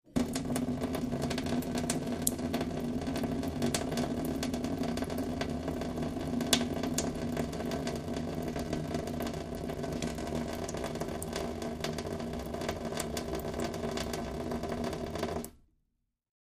Wash Basin Aluminum Trickle